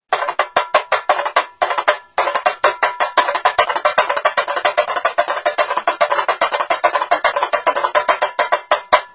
טמבורין
tambourin.wma